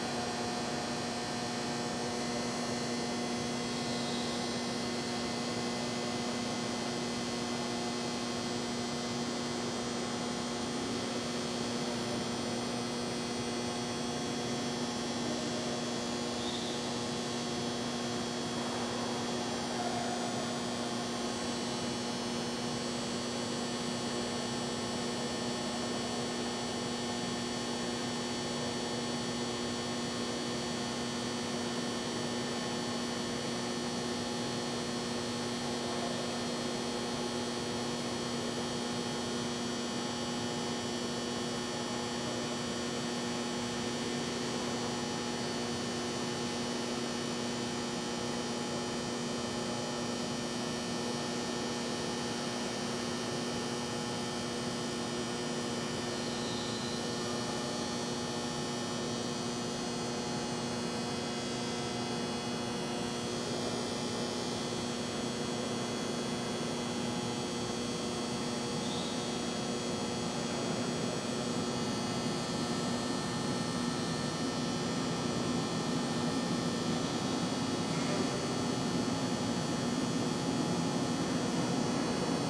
蒙特利尔 " 房间里的声响 凡尔登地铁的响亮电声嗡嗡声 加拿大蒙特利尔
描述：房间音Verdun地铁地铁大声电嗡嗡声嗡嗡声蒙特利尔，Canada.flac
Tag: 蒙特利尔 响亮 凡尔登 嗡嗡声 地铁 哼哼 地铁 房间 色调 加拿大